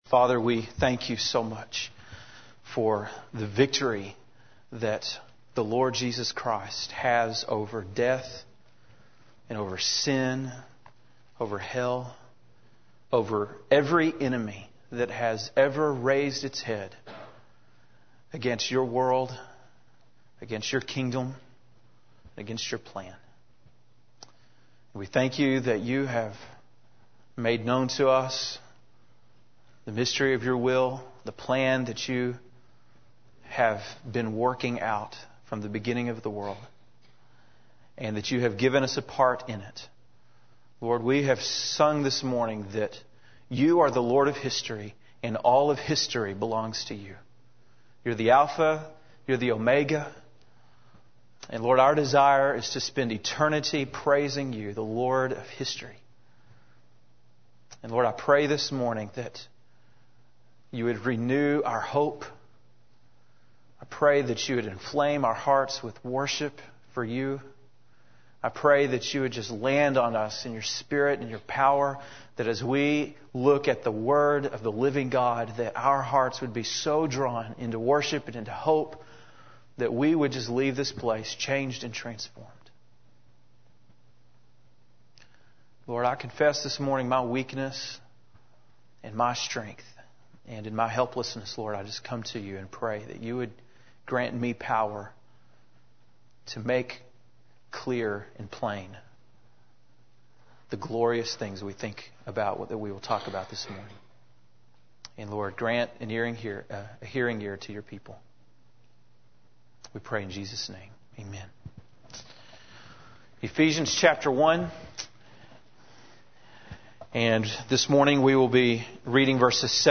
September 5, 2004 (Sunday Morning)